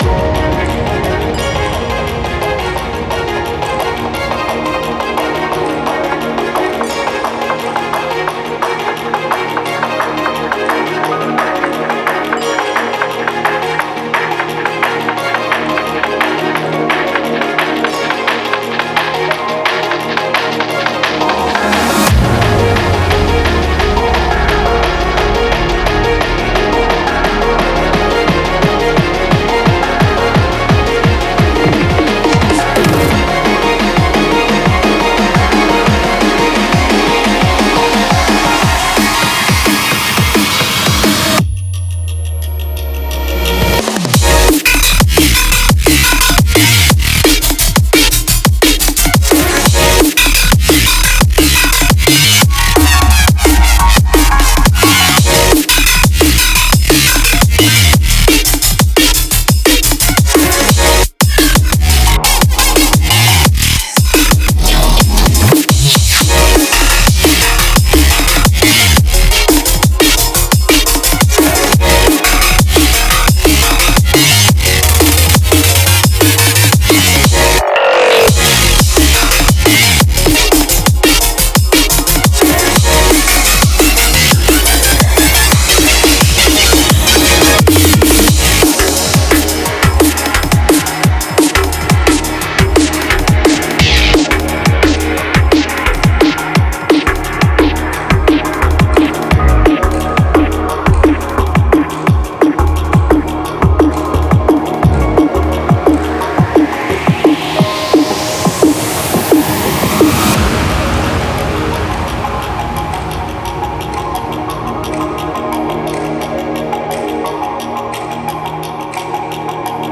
BPM174
Audio QualityMusic Cut
Genre: Drum n Bass